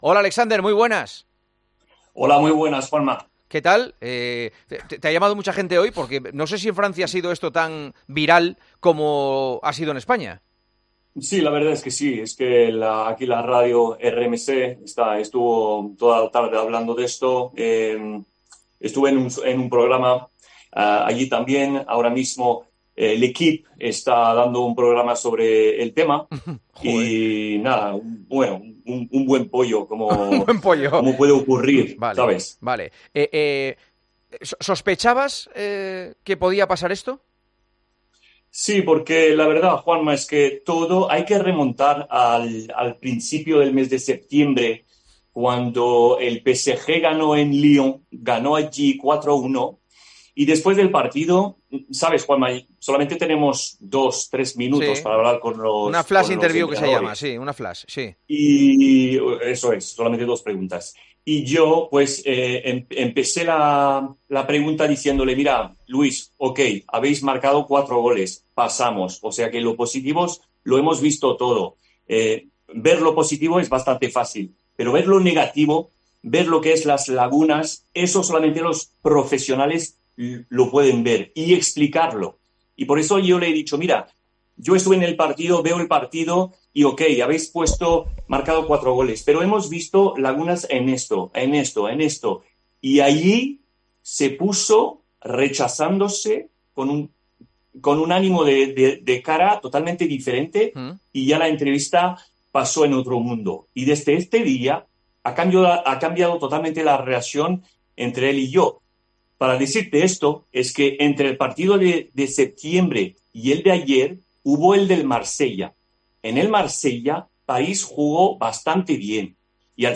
Juanma Castaño habló con el periodista